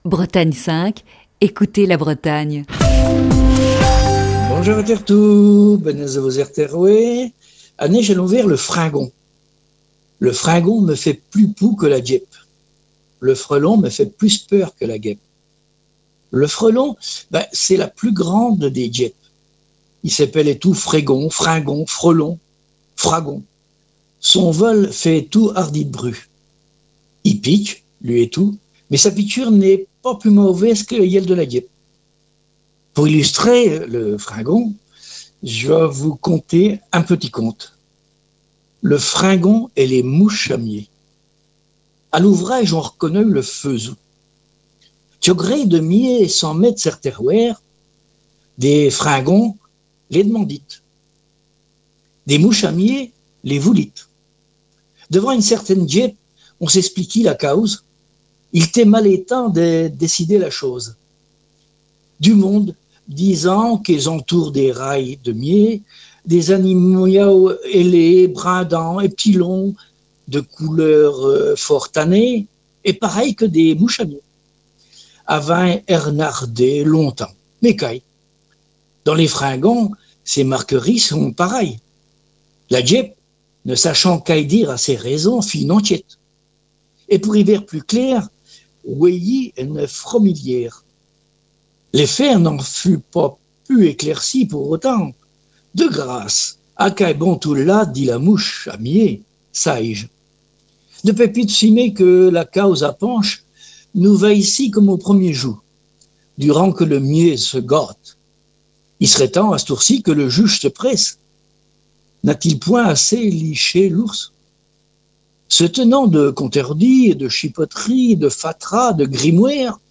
Retour en direct pour le Mot à kneute qui reprend ses habitudes dans la matinale.